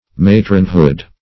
Matronhood \Ma"tron*hood\, n.